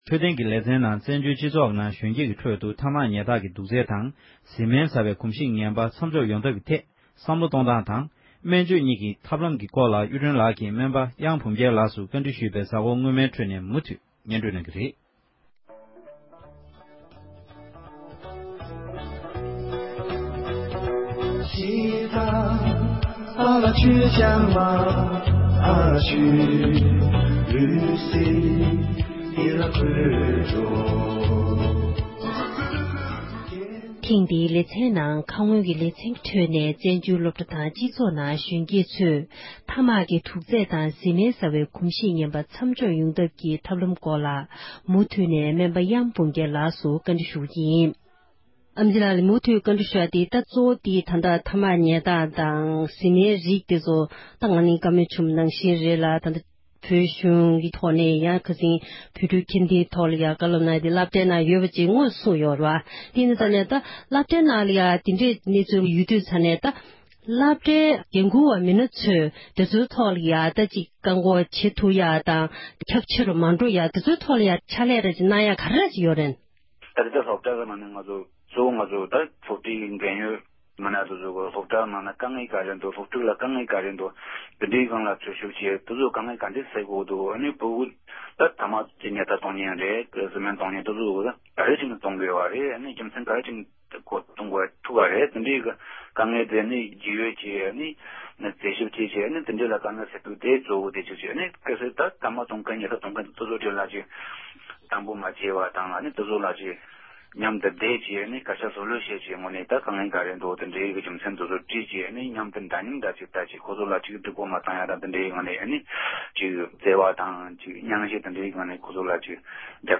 བཀའ་འདྲི་ཞུས་པའི་གཟའ་འཁོར་སྔོན་མའི་ཕྲོད་ནས་མུ་མཐུས་ནས་གསན་རོགས་གནང༌༎